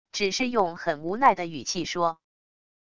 只是用很无奈的语气说wav音频